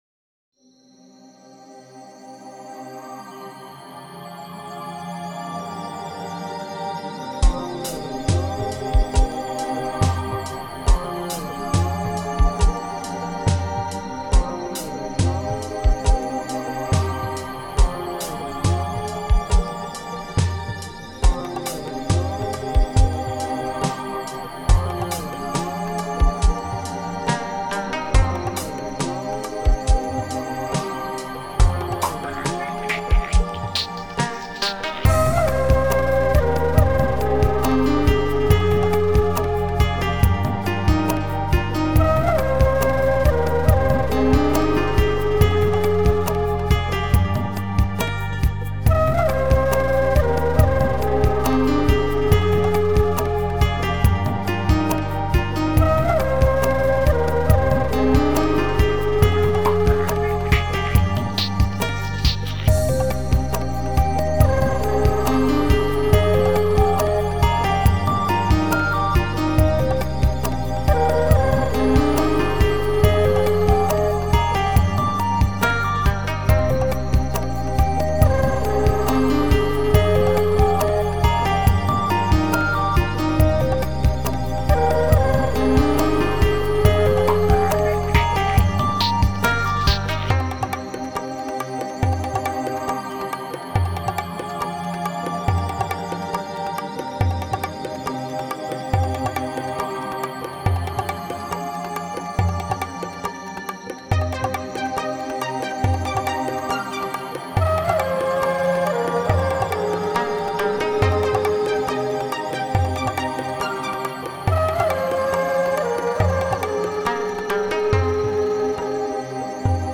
Genre: newage, enigmatic.